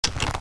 Index of /Downloadserver/sound/weapons/
bow_draw.mp3